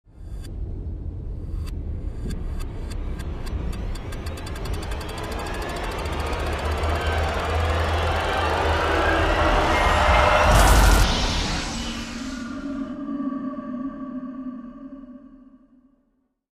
Звук обратной прокрутки времени при путешествиях в прошлое